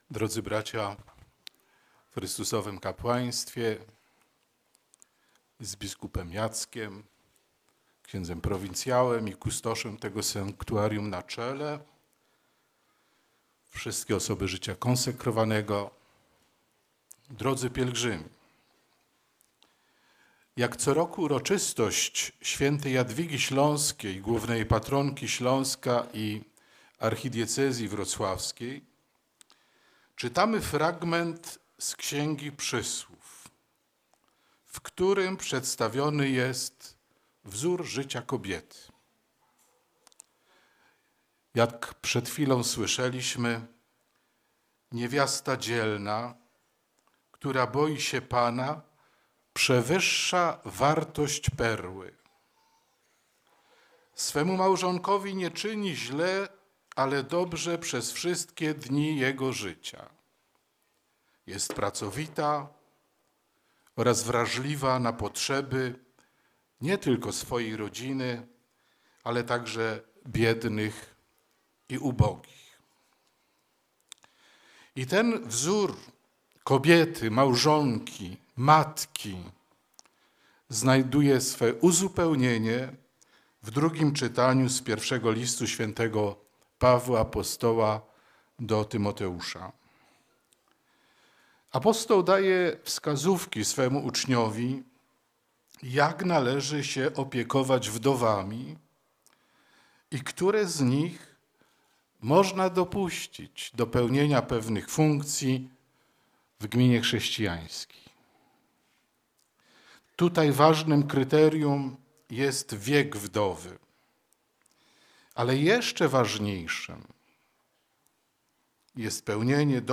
Małżeństwa, w których małżonkowie są dla siebie wzajemnym darem i rodziny budujące swoje relacje na logice daru, są w stanie stworzyć atmosferę sprzyjającą pełnemu rozwojowi wszystkich i dojrzewaniu w świętości – mówił abp Józef Kupny do kilkunastu tysięcy pielgrzymów, zgromadzonych na placu przy sanktuarium św. Jadwigi w Trzebnicy.
homilia-abpa-Kupnego.mp3